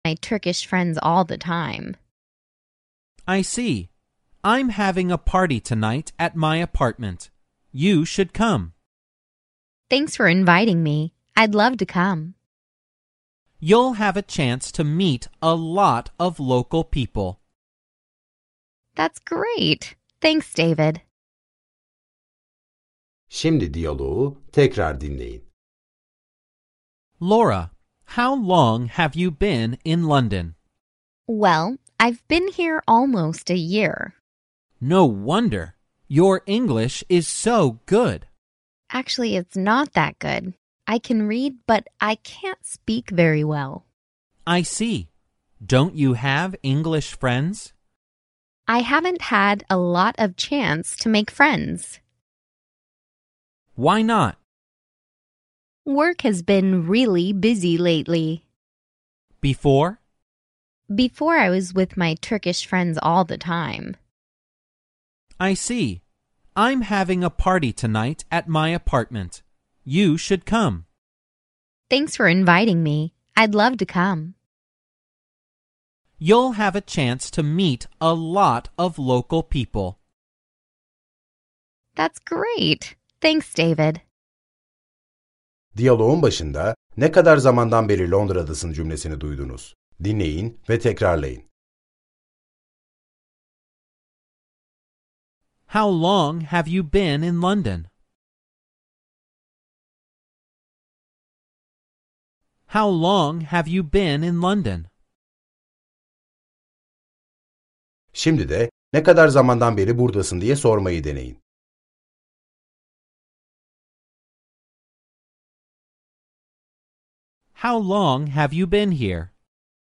Az sonra İngilizce öğrenmenin keyifli dünyasına adım atacaksınız. Dersler boyunca sizi yönlendiren bir kişisel eğitmeniniz olacak. Ana dili İngilizce olan iki kişi de sürekli diyalog halinde olacaklar.